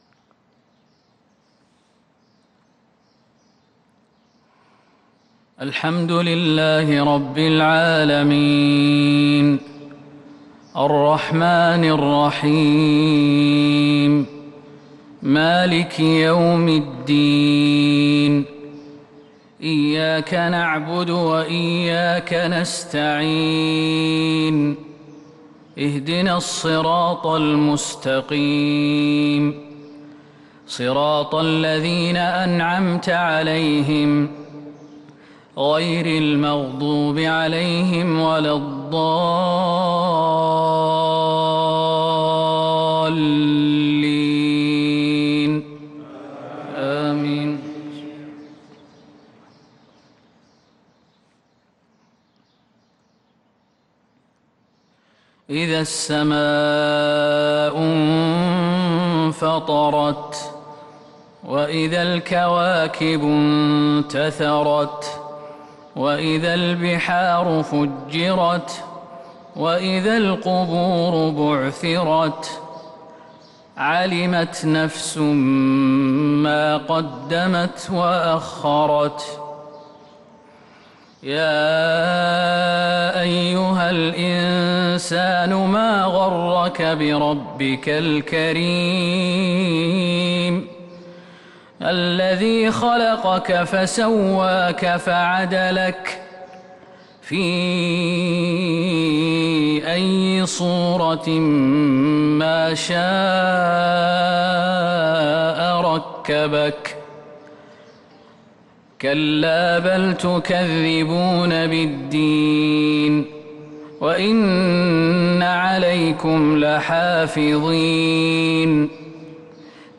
صلاة الفجر للقارئ خالد المهنا 6 رمضان 1443 هـ